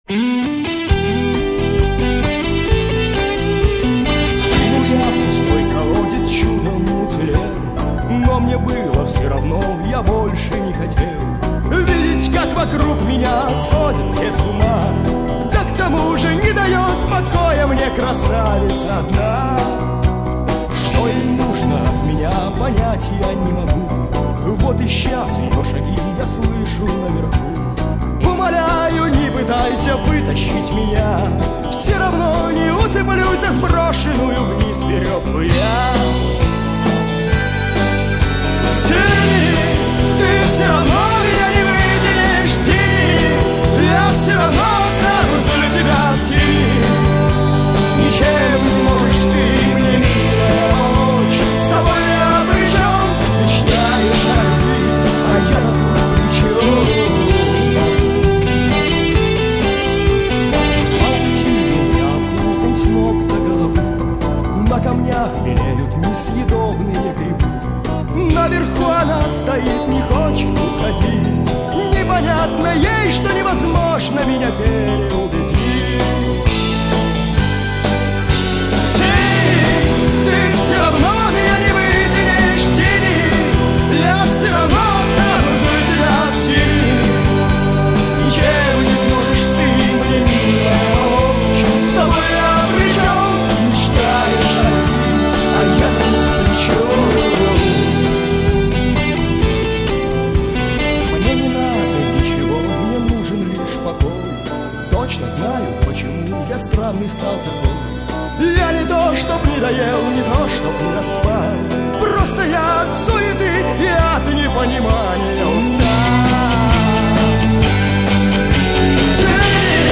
погружает слушателя в атмосферу мрачного театра абсурда